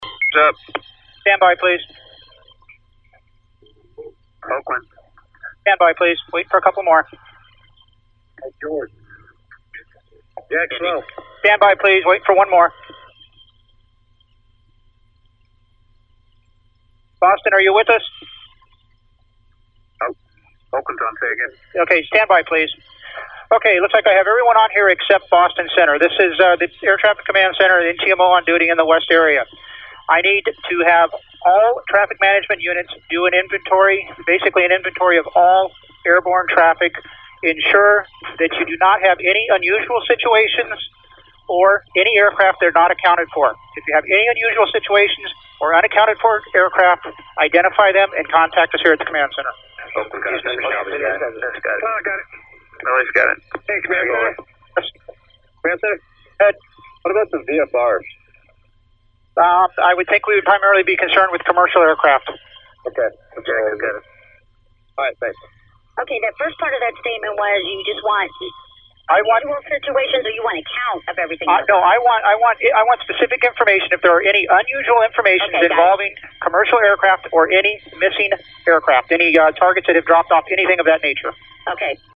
Second, he ordered an inventory of all aircraft in the air; the subject of a 9:30 call by one of Sliney’s traffic managers that can be heard at this link.